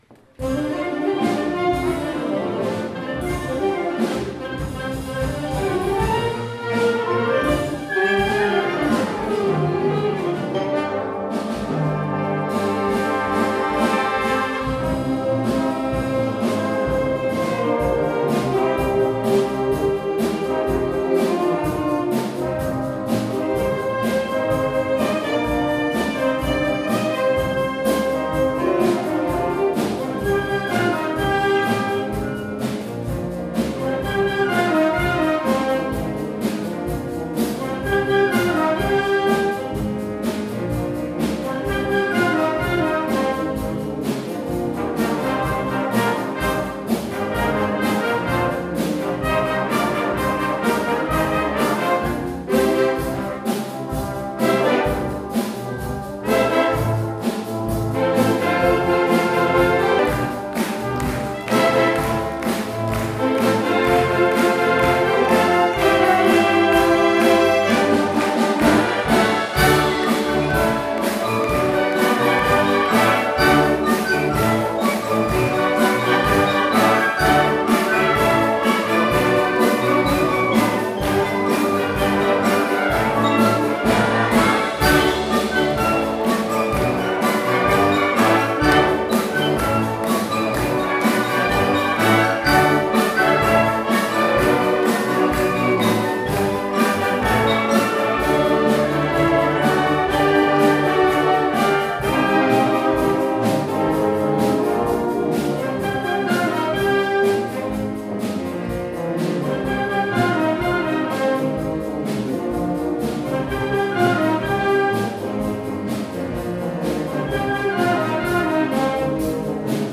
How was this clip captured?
Concert de Printemps 2 avril 2023